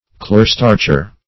Search Result for " clearstarcher" : The Collaborative International Dictionary of English v.0.48: Clearstarcher \Clear"starch`er\ (kl[=e]r"st[aum]rch`[~e]r), n. One who clearstarches.